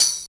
50 TAMB   -L.wav